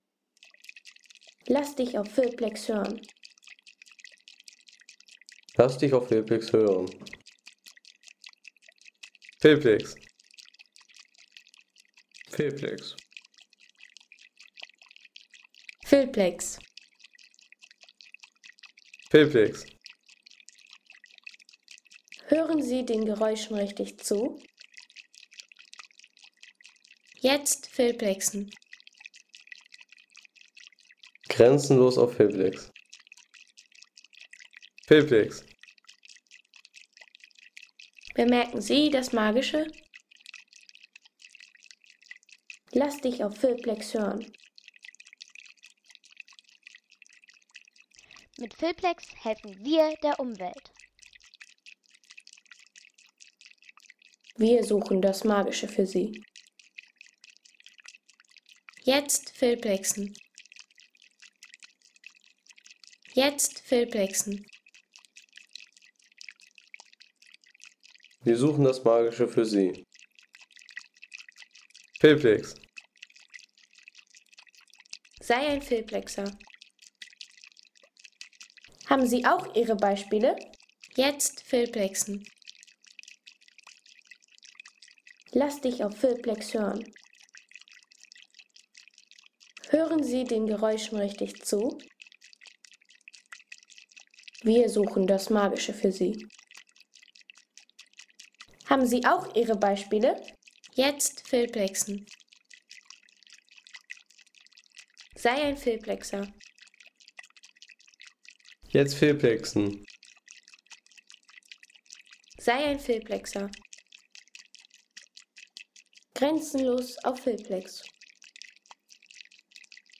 Kokoswasser / Kokosnuss
Kokoswasser / Kokosnuss Home Sounds Natur Pflanzen Kokoswasser / Kokosnuss Seien Sie der Erste, der dieses Produkt bewertet Artikelnummer: 65 Kategorien: Natur - Pflanzen Kokoswasser / Kokosnuss Lade Sound....